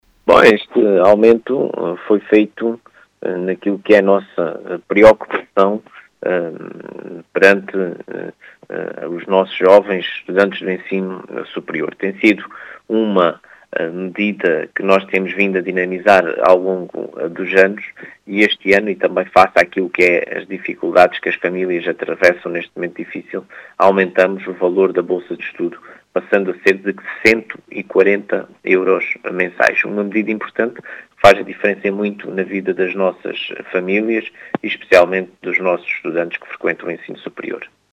As explicações são do presidente da Câmara Municipal de Ourique, Marcelo Guerreiro.